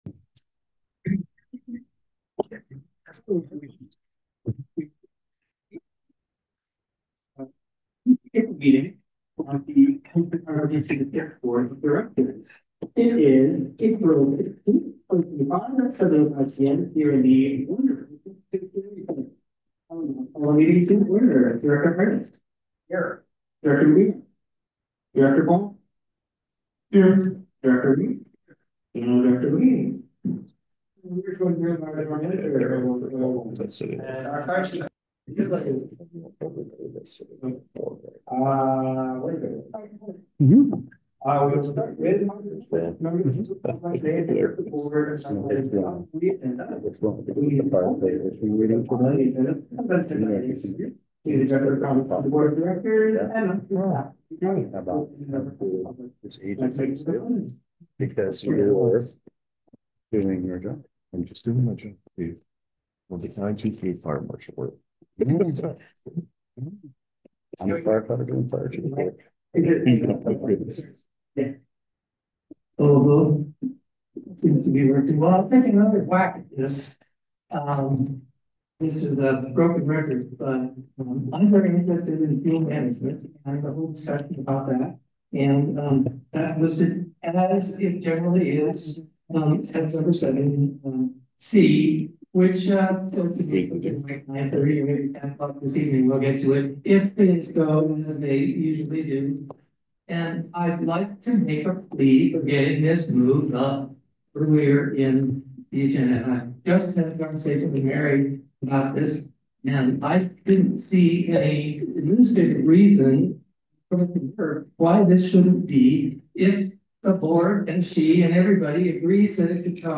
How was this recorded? Please note there was a wifi connection issues and the quality of the audio/video maybe affected.